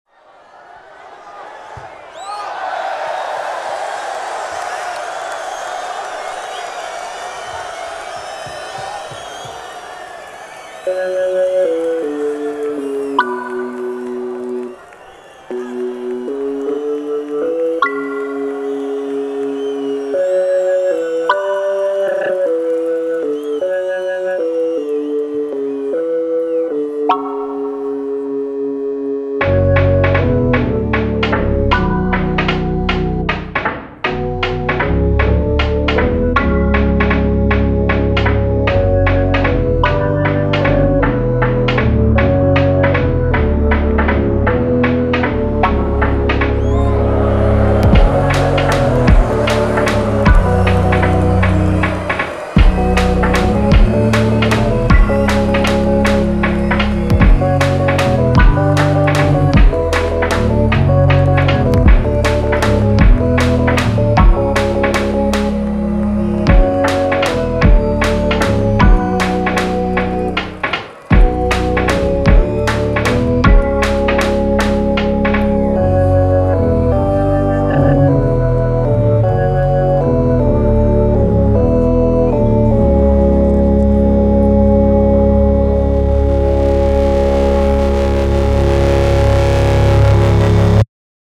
this started as a kalimba jam but once i found this loop, i wanted an 80s-ish power-ballad-esque drum sound. also took inspiration for the bass from future islands’ seasons (waiting on you). so maybe a late 80s narrative dog walk drama and not a reality show? idk
✅ A Kalimba !